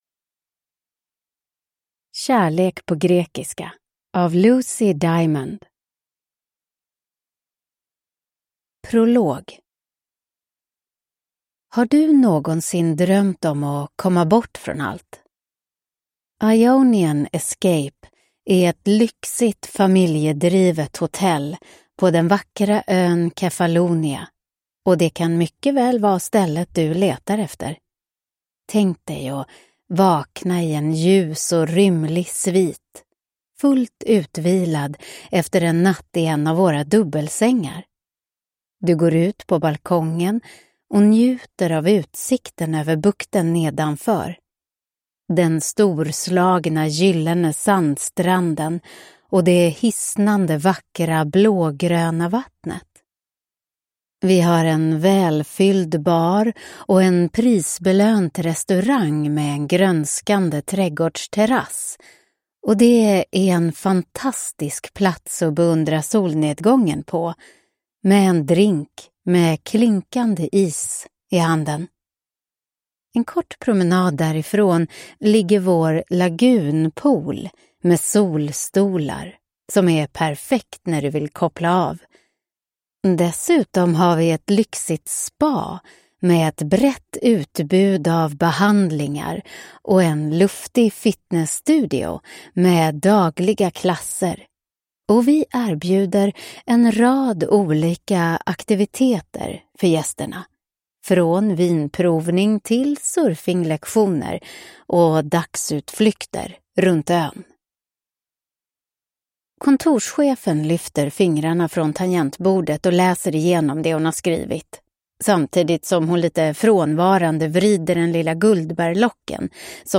Kärlek på grekiska – Ljudbok